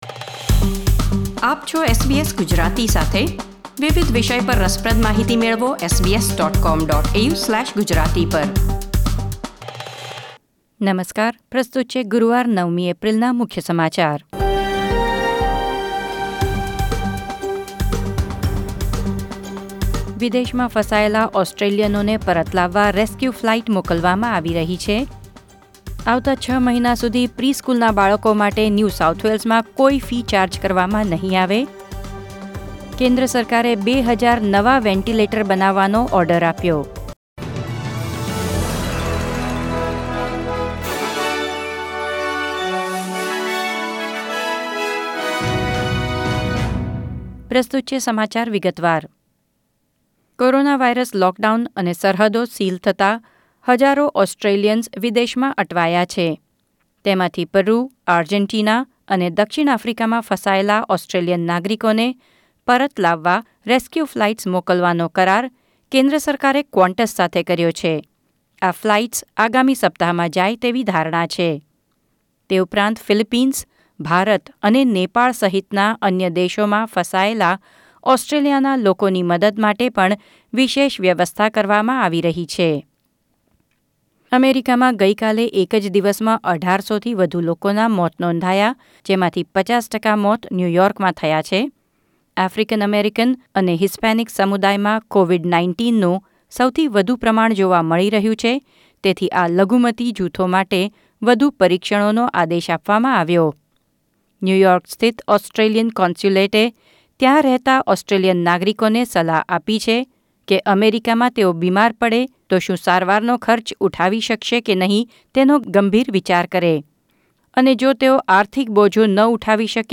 ૯ એપ્રિલ ૨૦૨૦ના મુખ્ય સમાચાર